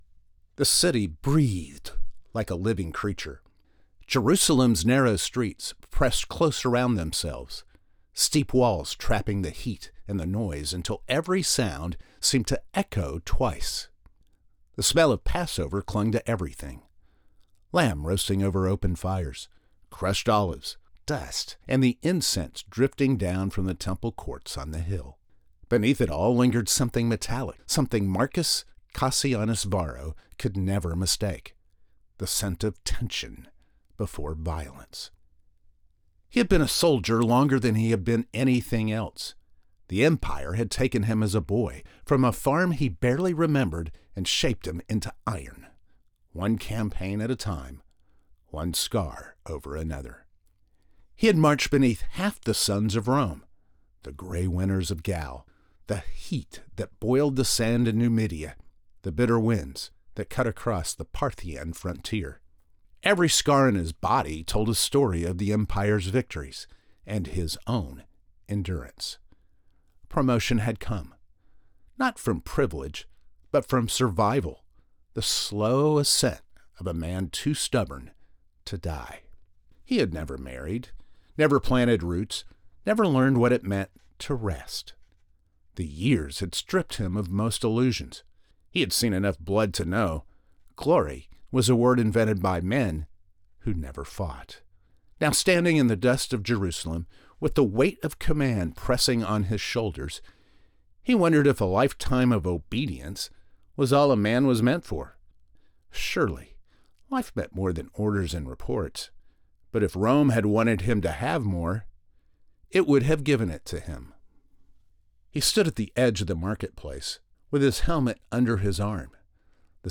Audio Book Voice Over Narrators
Adult (30-50) | Older Sound (50+)